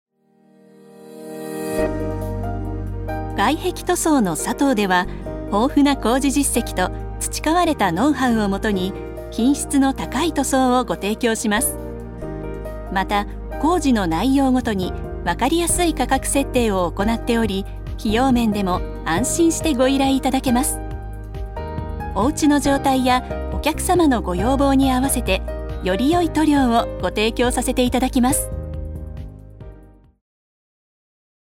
女性タレント
ナレーション１